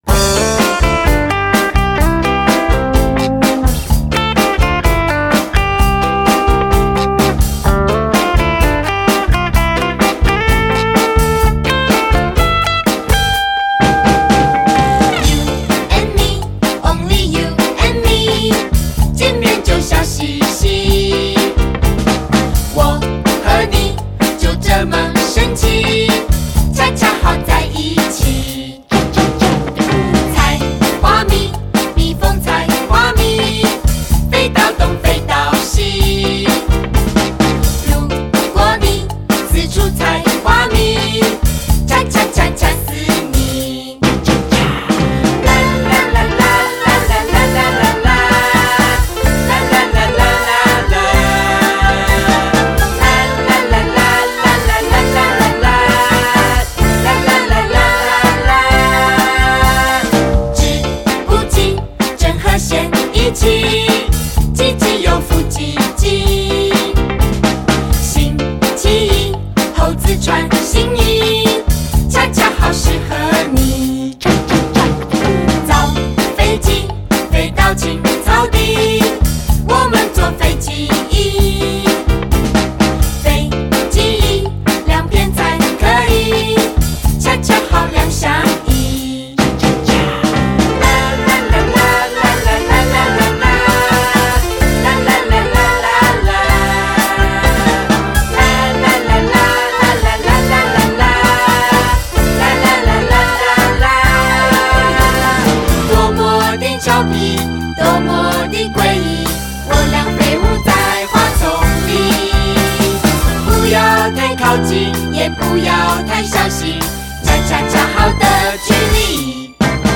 俗又有力恰恰舞曲.笑到絕倒無厘頭歌詞,復古風味大快人心